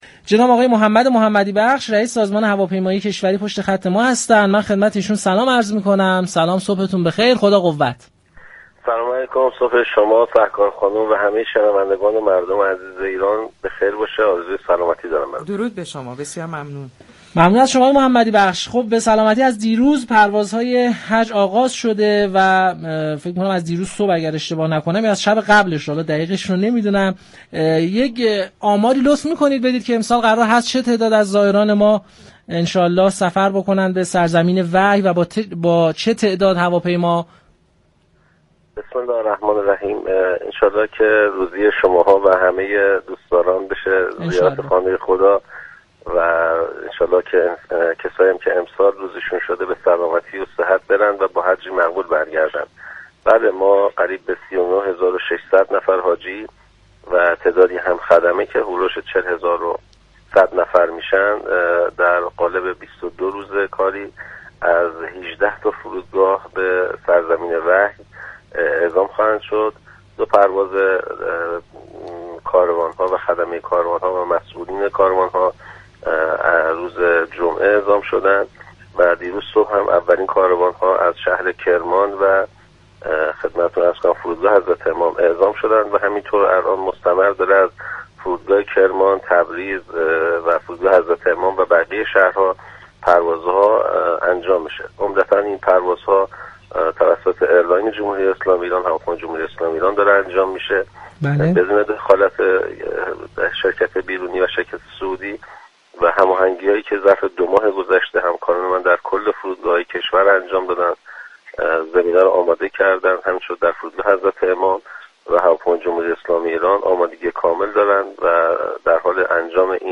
به گزارش پایگاه اطلاع رسانی رادیو تهران، محمد محمدی بخش رئیس سازمان هواپیمایی كشوری در گفتگو با پارك شهر رادیو تهران با بیان اینكه هواپیمایی «ایران ایر» پروازهای حج را بر عهده دارد گفت: نزدیك به 39 هزار و 600 نفر زائر به همراه 40 هزار و 100 نفر خدمه در قالب 22 روز كاری به سرزمین وحی اعزام خواهند شد. 18 فرودگاه كشور پذیرای حجاج ایرانی هستند.